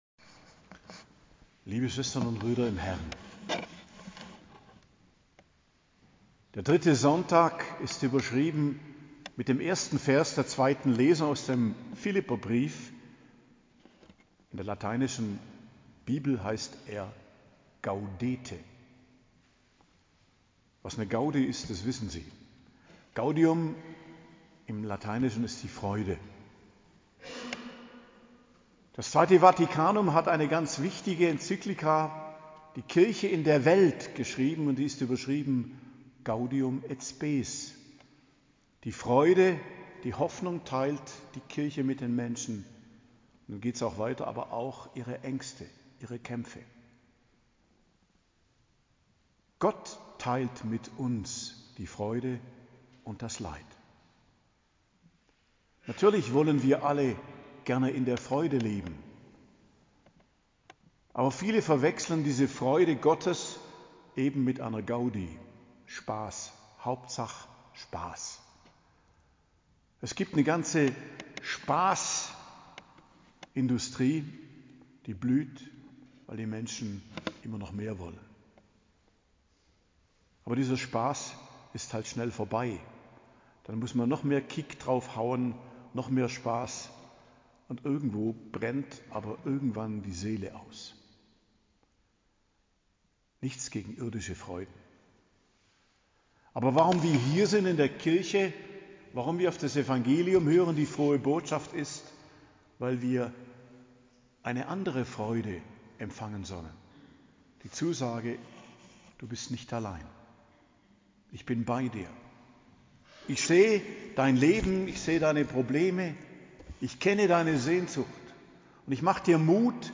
Predigt zum 3. Adventssonntag, 15.12.2024 ~ Geistliches Zentrum Kloster Heiligkreuztal Podcast